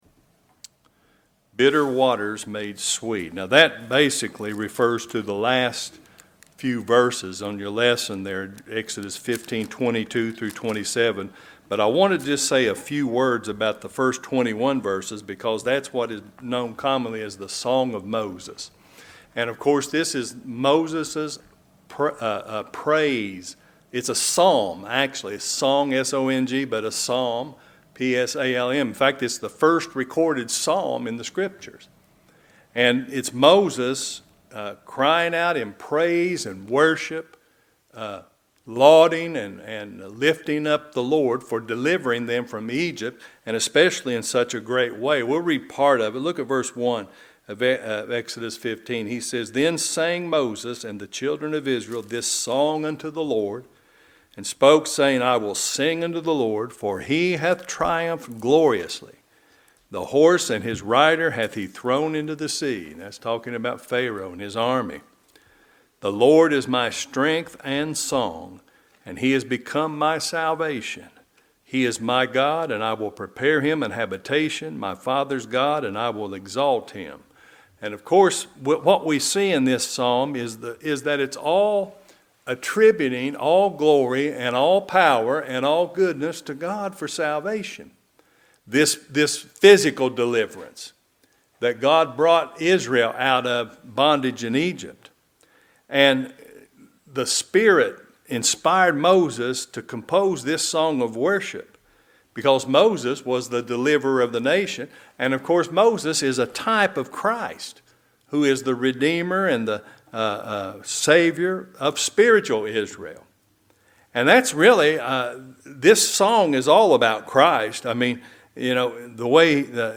Bitter Waters Made Sweet | SermonAudio Broadcaster is Live View the Live Stream Share this sermon Disabled by adblocker Copy URL Copied!